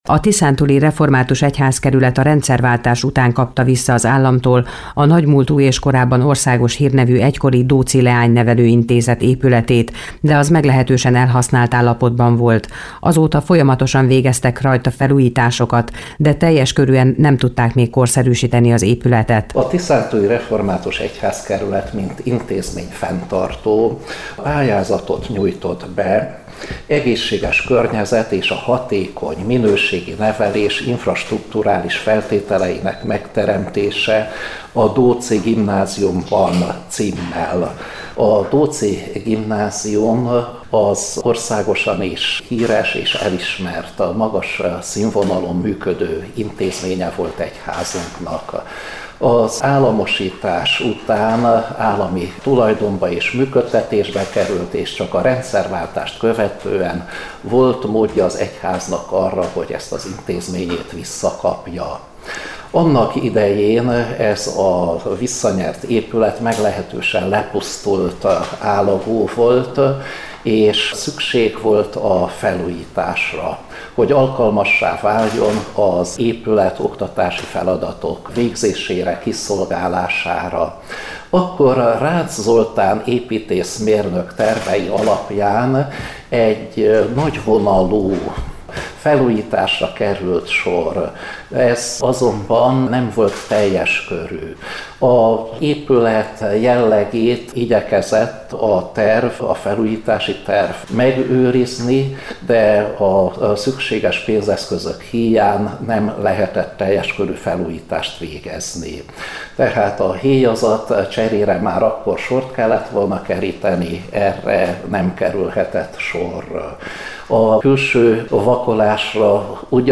A Dóczy Gimnázium felújításáról szóló sajtótájékoztatón készült riport-összeállítást itt meghallgathatja.